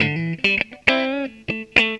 GTR 2  AM.wav